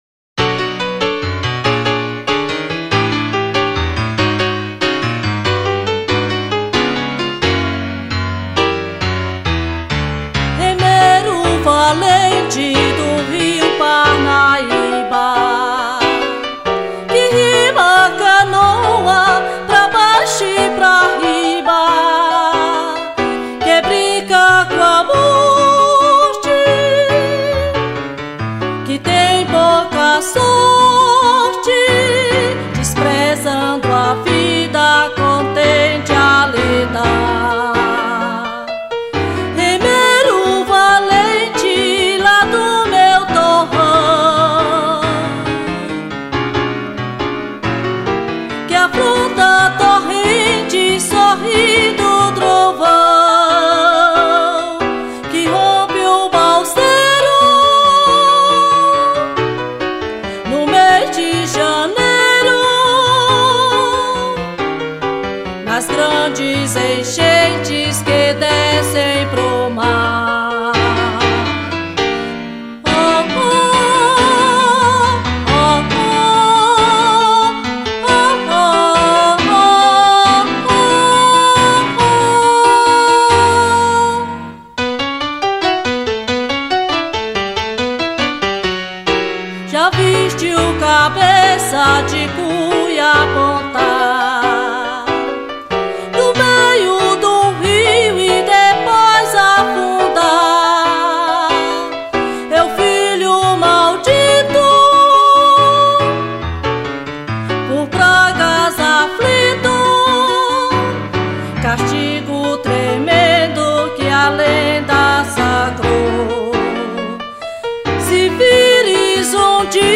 309   03:01:00   Faixa:     Canção Nordestina